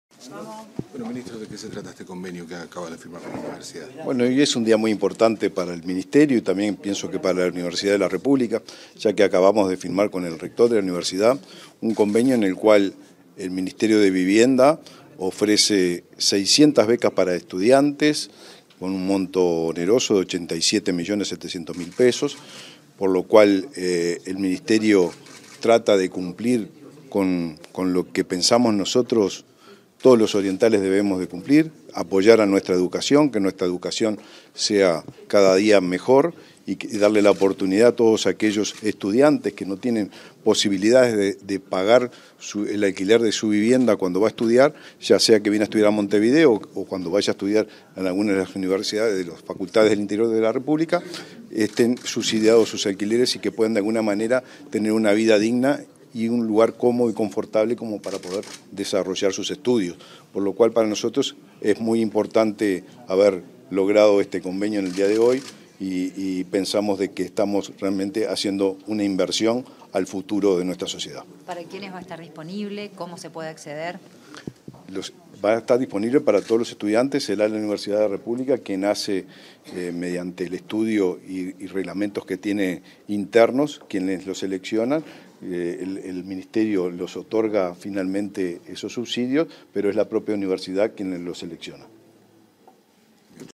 Declaraciones del ministro de Vivienda y Ordenamiento Territorial, Raúl Lozano
Declaraciones del ministro de Vivienda y Ordenamiento Territorial, Raúl Lozano 21/06/2024 Compartir Facebook X Copiar enlace WhatsApp LinkedIn Tras la firma de un convenio con la Universidad de la República (Udelar), este 21 de junio, para facilitar la garantía de alquiler a estudiantes universitarios, el ministro de Vivienda y Ordenamiento Territorial, Raúl Lozano, realizó declaraciones a medios informativos.